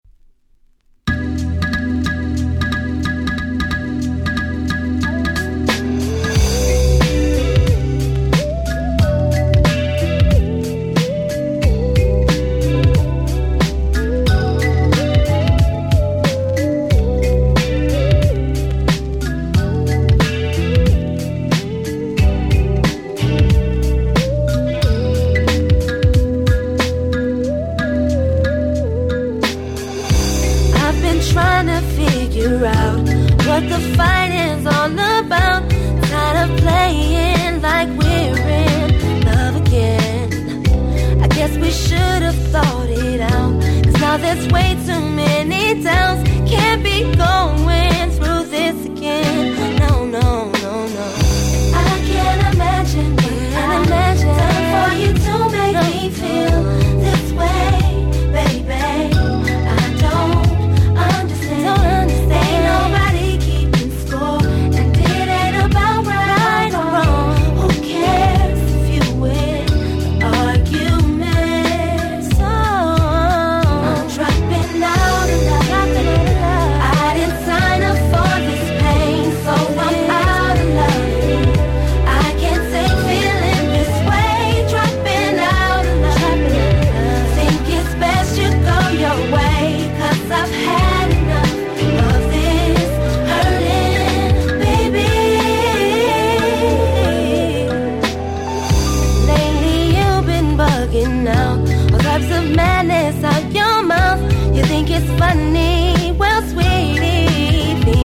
08' Nice R&B !!